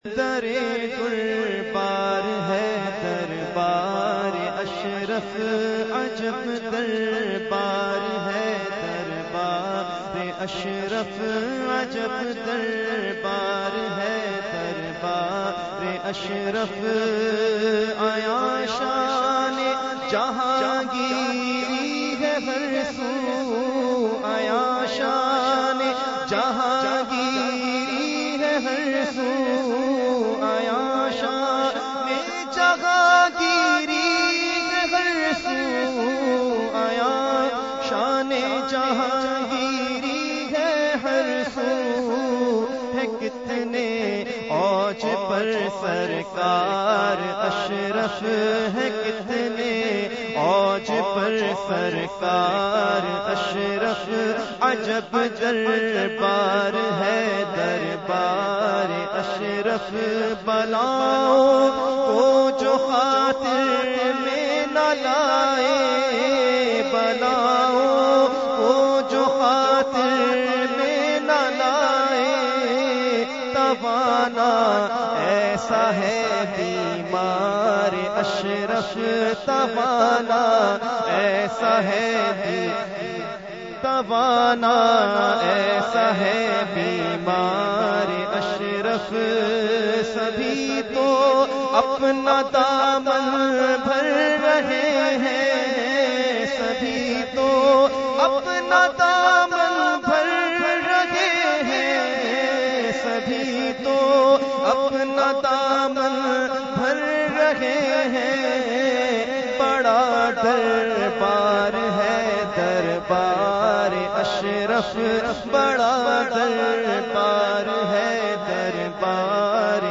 Category : Manqabat | Language : UrduEvent : Urs Makhdoome Samnani 2016